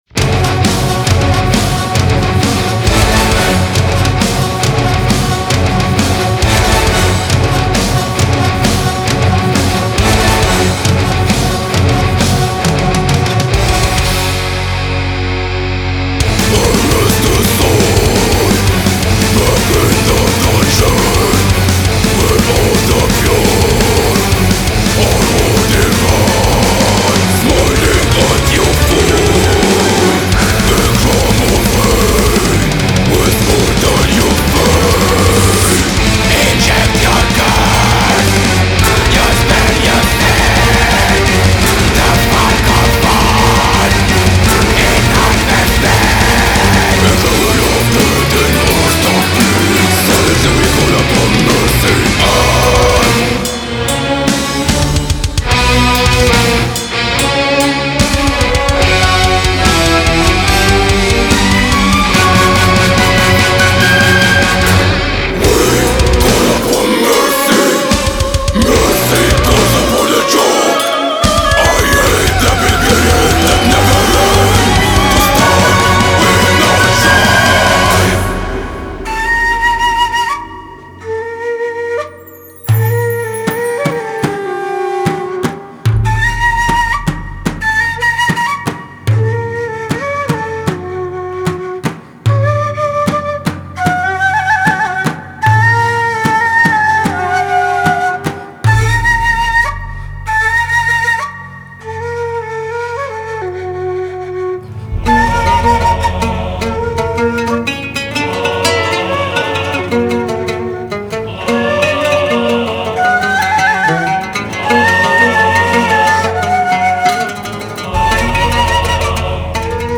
Black Metal Фолк Рок